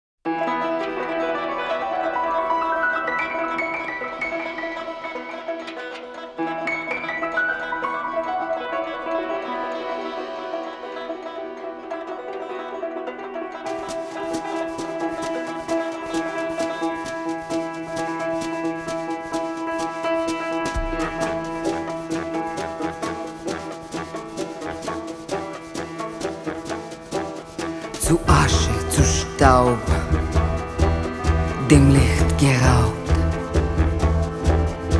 Жанр: Джаз